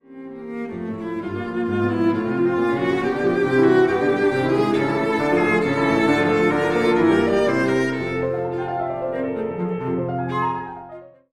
mp3Farrenc, Louise, Cello Sonata in Bb, Op. 46, mvt. I. Allegro moderato, mm.82-86